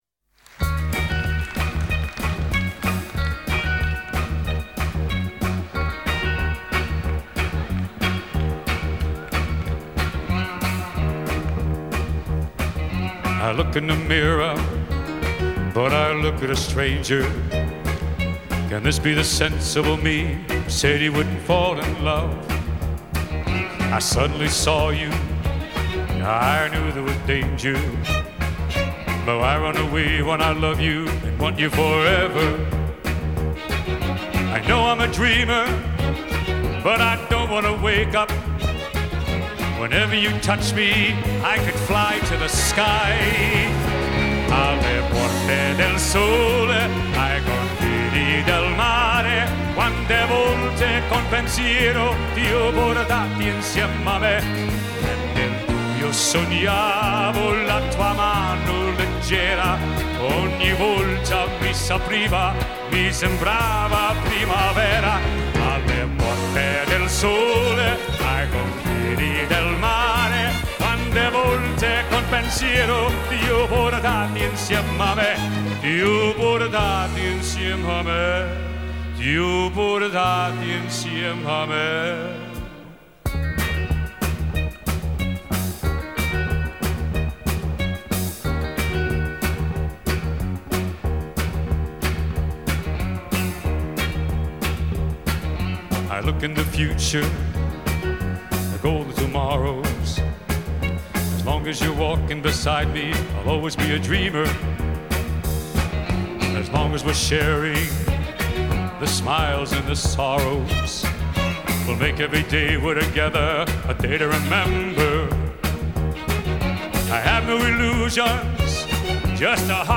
Genre:Pop
Style:Vocal